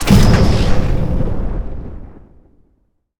sci-fi_explosion_05.wav